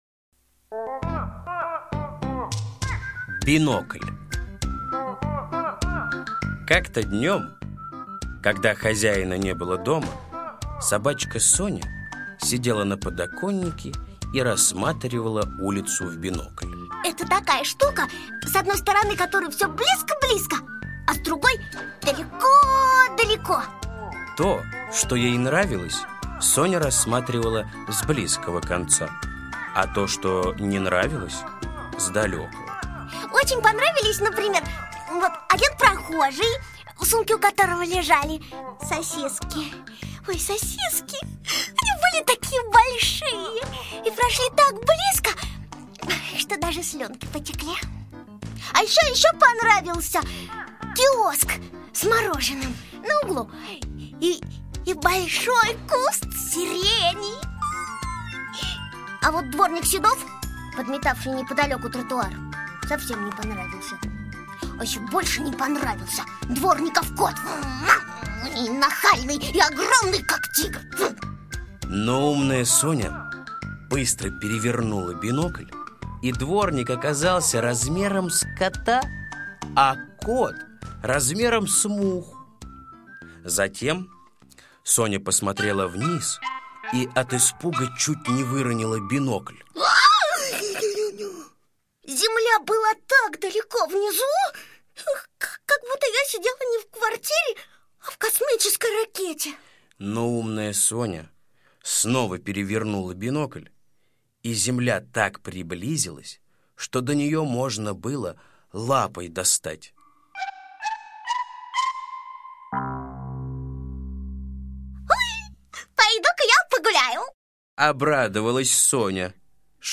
Бинокль - аудиосказка Усачева А.А. Собачка Соня рассматривала улицу в бинокль. Поворачивая бинокль, она приближала или отдаляла предметы.